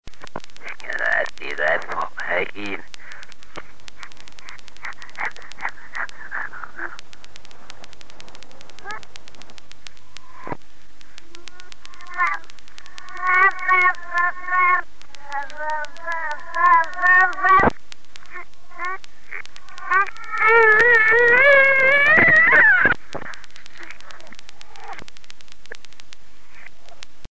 A felvétel eredetiben alapvetően gyors és magas hangokat tartalmaz - a hétköznapi beszédhez képest. Más sebességekkel meghallgatva észrevehető, hogy fele olyan gyorsan lejátszva a hangok közelebb állanak a fülünkhöz.
Ezután nagyjából kivehető, hogy az archív felvétel egy férfihangot, és gyereksírást, gügyögést tartalmaz.